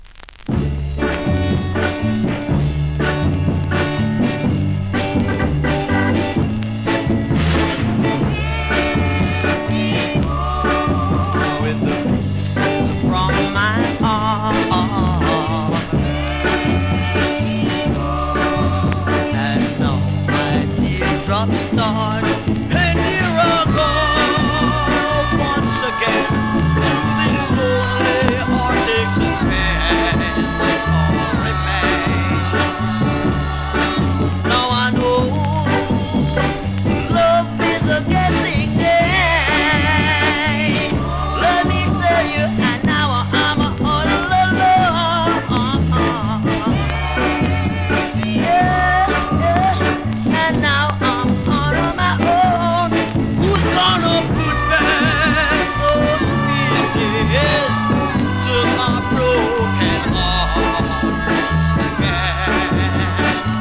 2019 NEW IN!!SKA〜REGGAE!!
スリキズ、ノイズ比較的少なめで